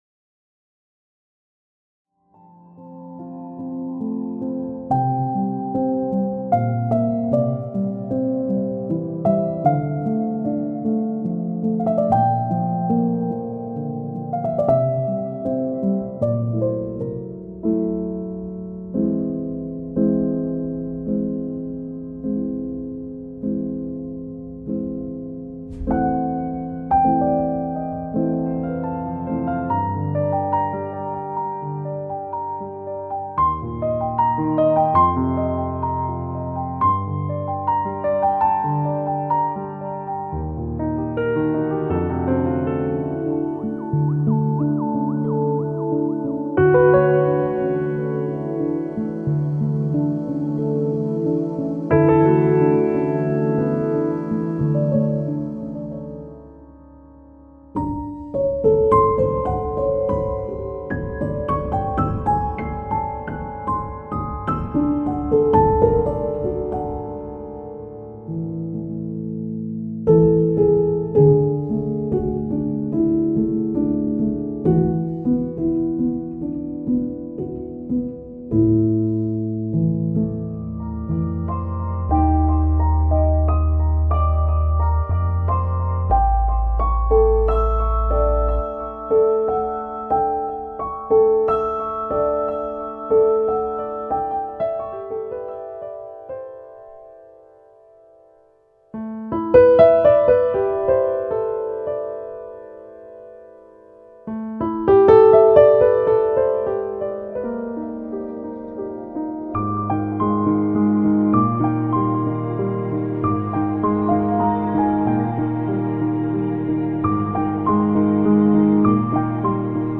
piano solo
• Genre: Ambient / Modern Classical